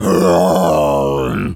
gorilla_growl_02.wav